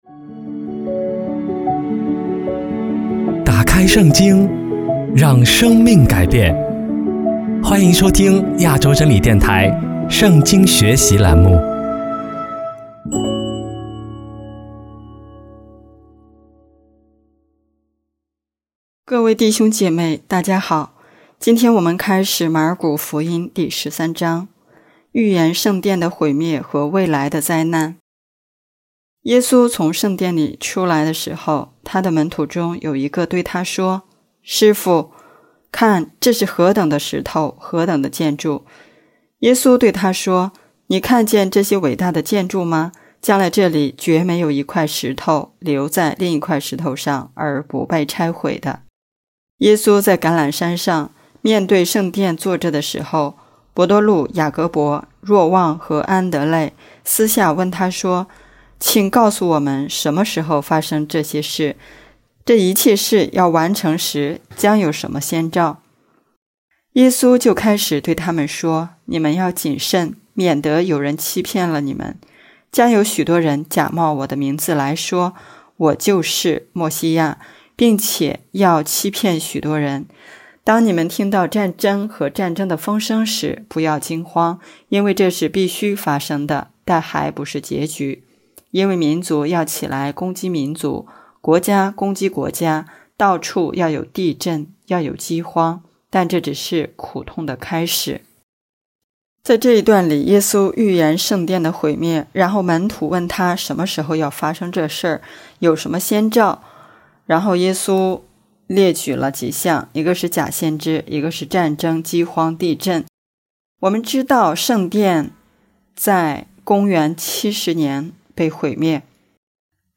【圣经课程】|马尔谷福音第十三讲